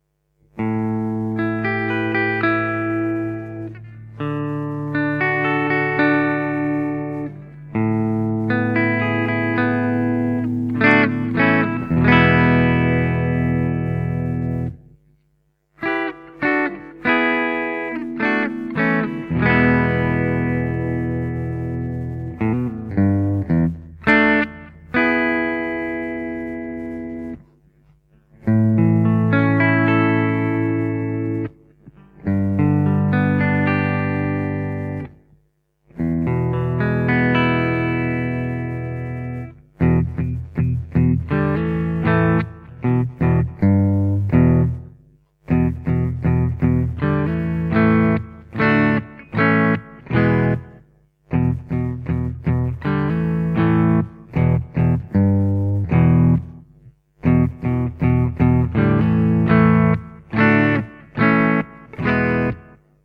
The Phoenix is a rich, creamy vintage humbucker reminiscent of the early 60's Pafs. Sweet and warm, the Phoenix provides beautiful, soulful sounds all the time.